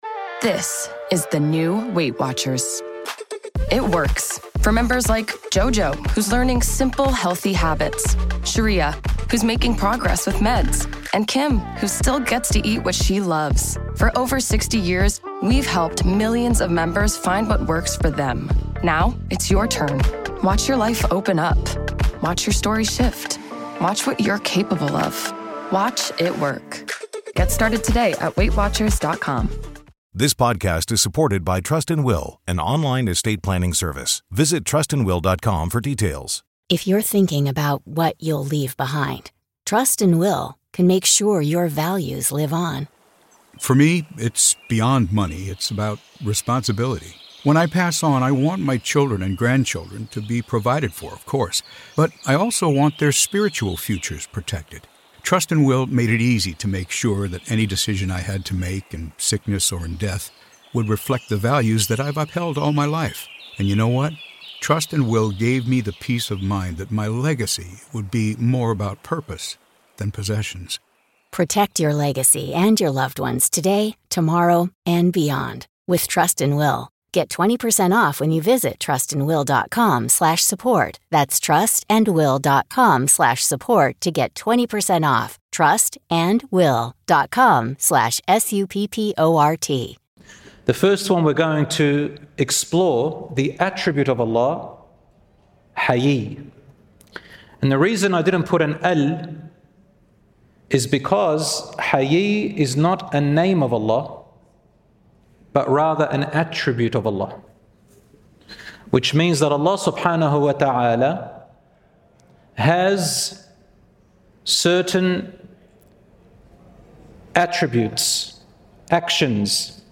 In this lecture, we explain the attribute of Allah 'Ḥayiyy', clarifying its true meaning. We explore how this attribute encourages self-respect and modesty, and guides us to live by high moral principles while strengthening our connection with Allah.